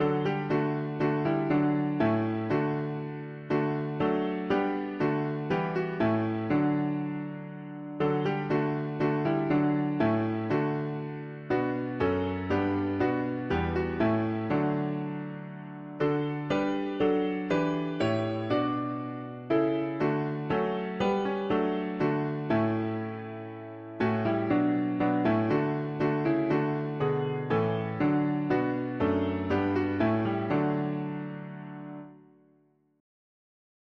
Key: D major Meter: 76.76 D